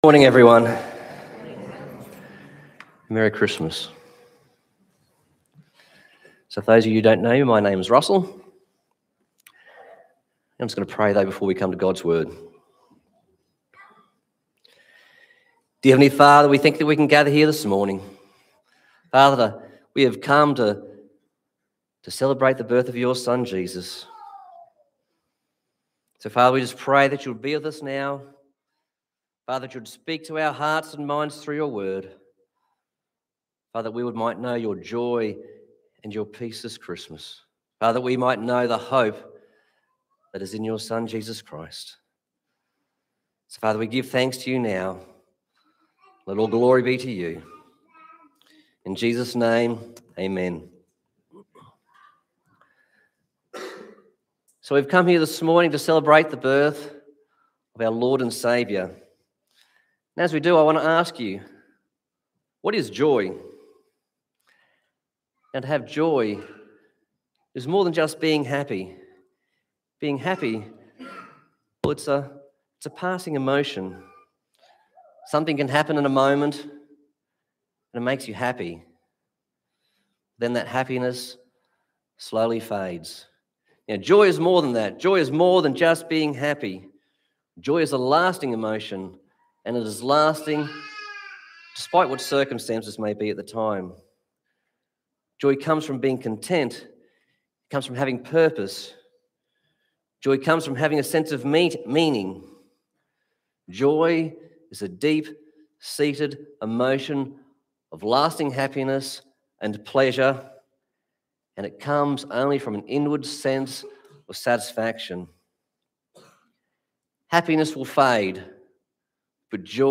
Christmas 2025 Passage: Luke 1:26-41, Luke 1:1-7, Luke 2:26-32 Service Type: Sunday Morning